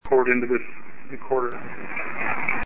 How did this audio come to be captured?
The following EVP examples were collected during the group tour of the Gable Lombard penthouse in the Hollywood Roosevelt Hotel. All are Class C to B quality voices.